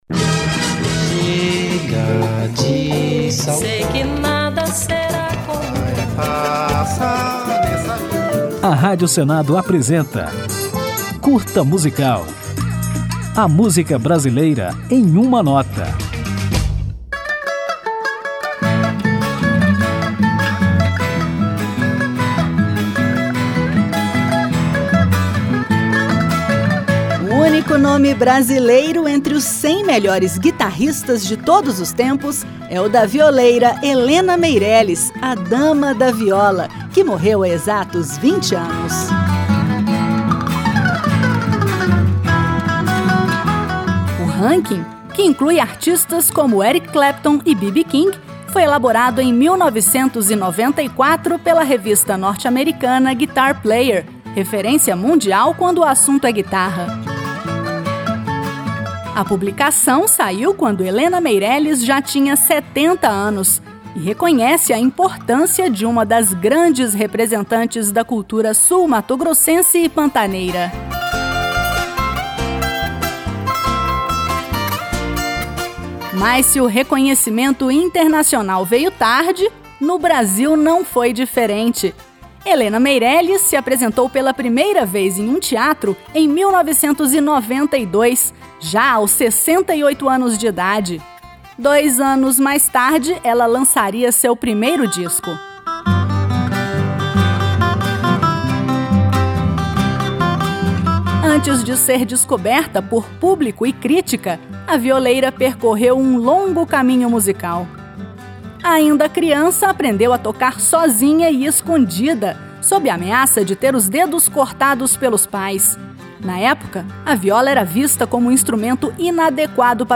Hoje, lembramos os 20 anos de morte da violeira sul-mato-grossense Helena Meirelles, o único nome brasileiro entre os cem melhores guitarristas de todos os tempos. Confira tudo sobre esta grande representante da cultura pantaneira na homenagem do Curta Musical, que toca a música Primeiro de Maio, lançada por Helena Meirelles em 2003, dois anos antes de sua morte.